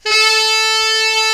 Index of /90_sSampleCDs/Giga Samples Collection/Sax/HARD + SOFT
TENOR HARD.2.wav